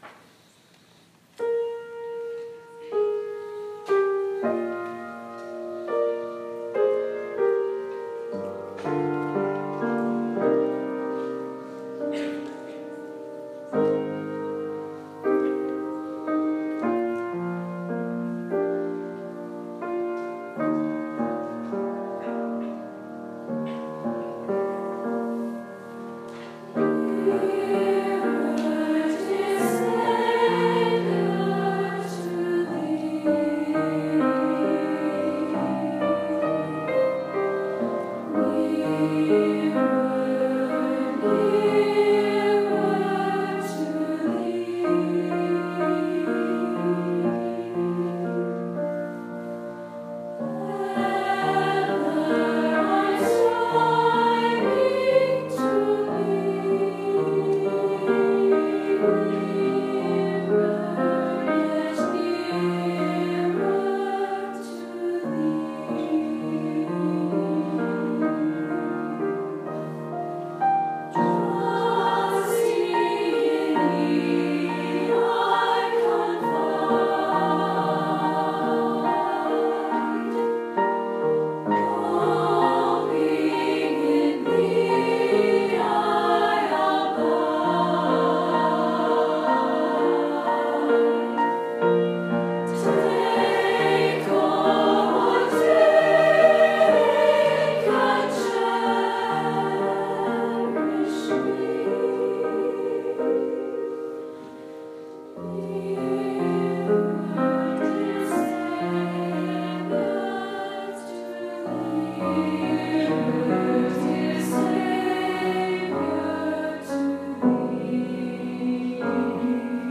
SAB and piano.
NearerDearSavior_stakeconf.m4a